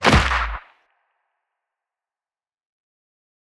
Toon punch 2.wav